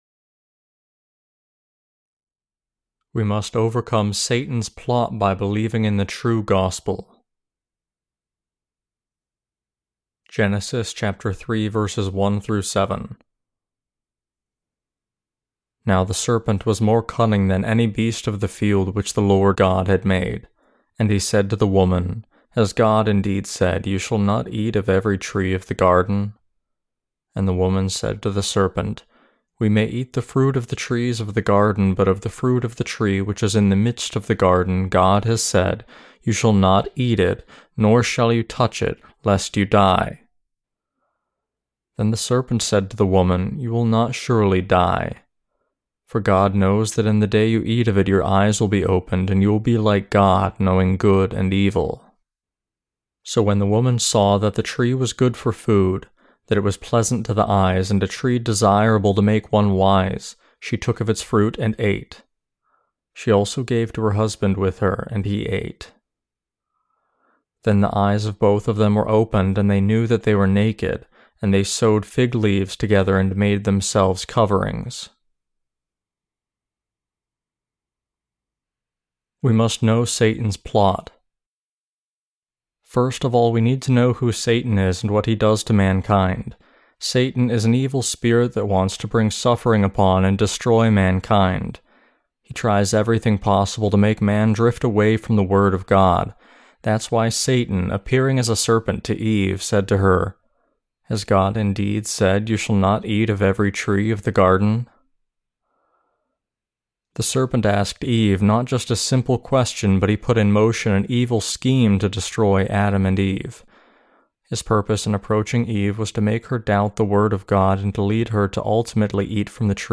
Sermons on Genesis (II) - The Fall of Man and The Perfect Salvation of God Ch3-6.